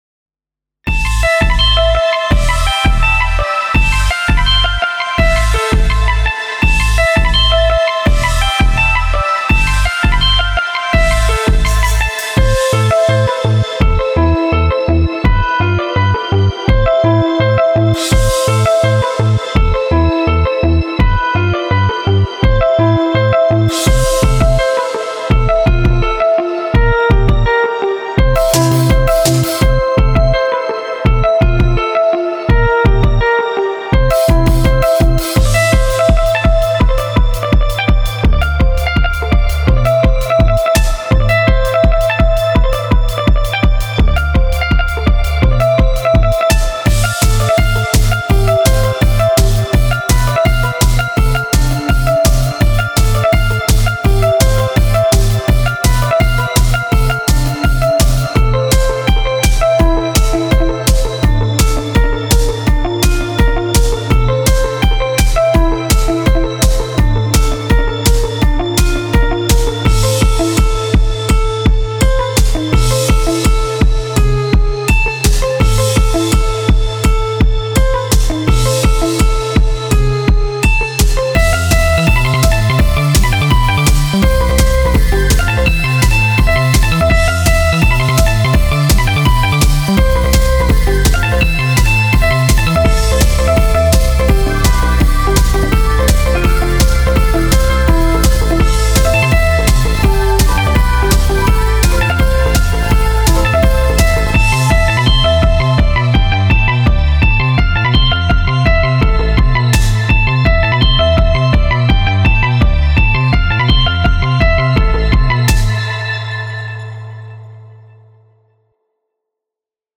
BGM
アップテンポロング暗い